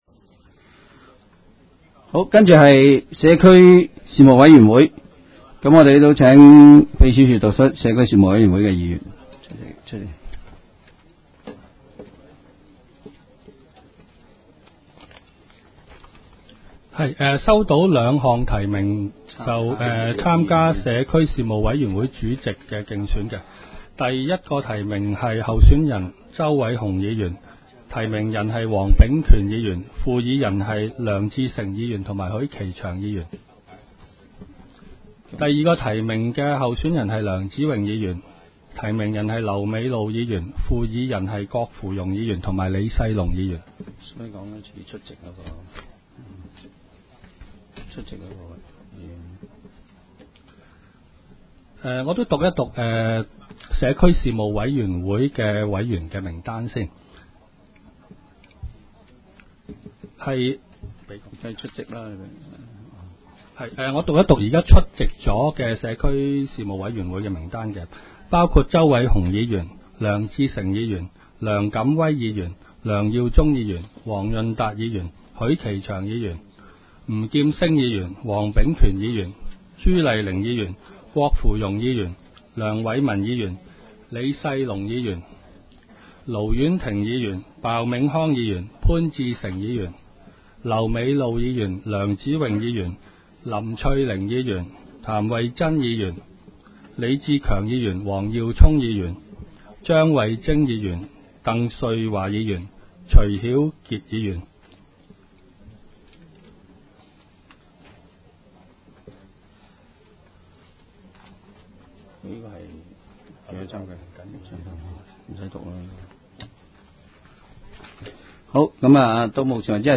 委员会会议的录音记录
社区事务委员会第一次特别会议会议 日期: 2016-01-19 (星期二) 时间: 下午3时24分 地点: 香港葵涌兴芳路166-174号 葵兴政府合署10楼 葵青民政事务处会议室 议程 讨论时间 开会词 00:07:33 1. 选举社区事务委员会主席及副主席 00:19:30 全部展开 全部收回 议程: 开会词 讨论时间: 00:07:33 前一页 返回页首 议程:1.